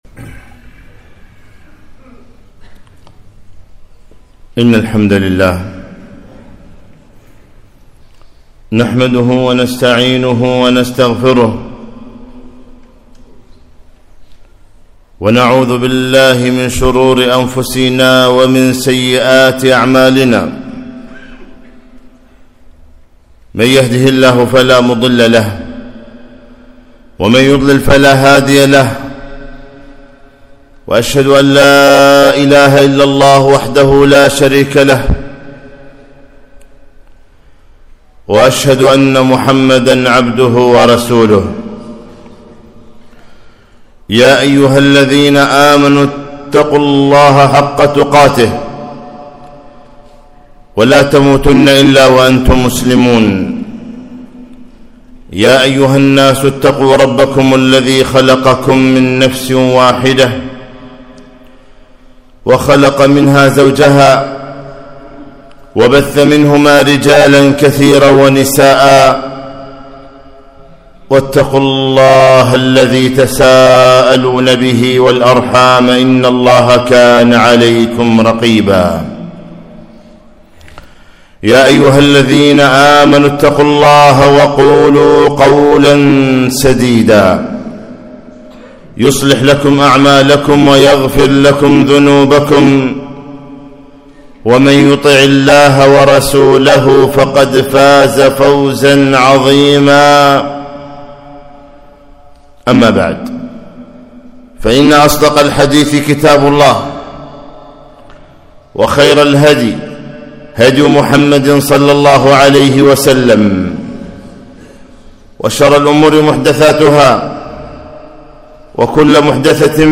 خطبة - ماذا تريد؟